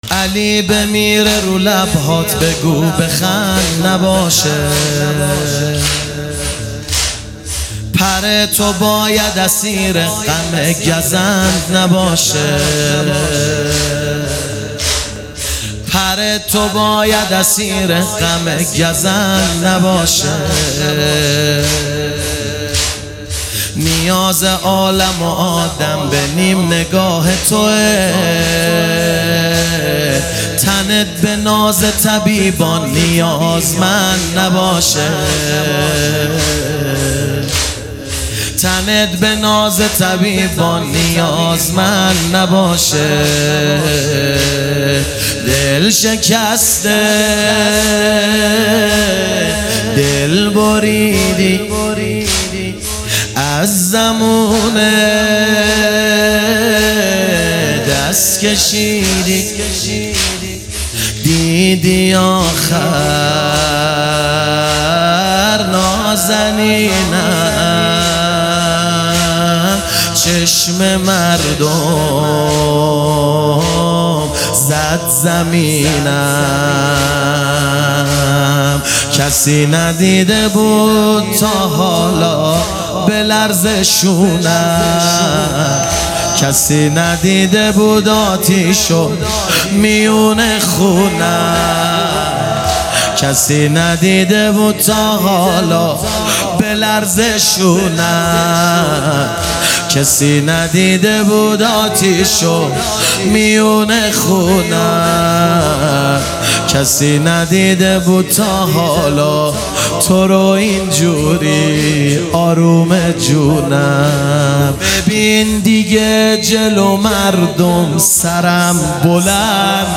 ایام فاطمیه 1399